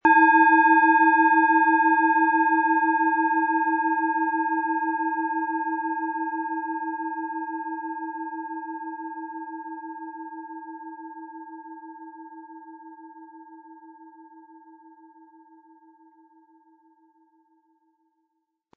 Planetenschale® Innere Tiefe fühlen & Meditationen mit beruhigten Gedanken mit Alphawellen, Ø 10,5 cm, 100-180 Gramm inkl. Klöppel
Alphawelle
Spielen Sie die Schale mit dem kostenfrei beigelegten Klöppel sanft an und sie wird wohltuend erklingen.
HerstellungIn Handarbeit getrieben
MaterialBronze